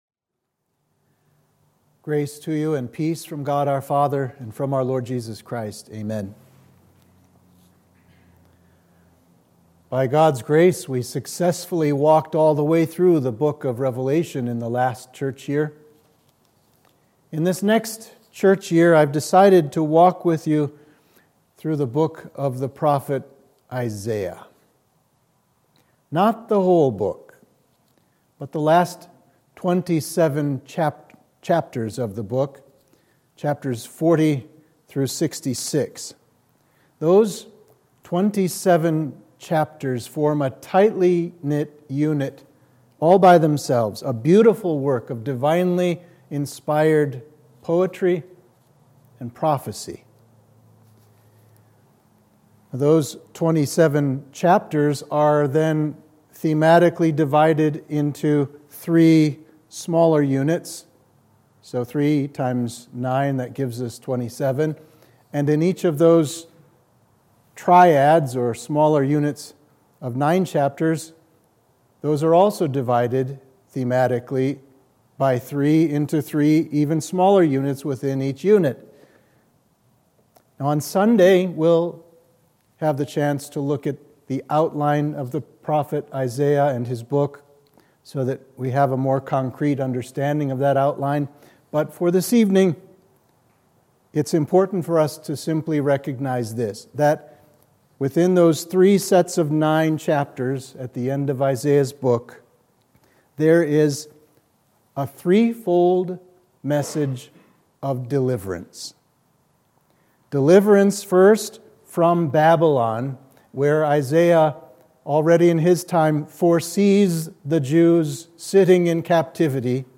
Sermon for Midweek of Advent 1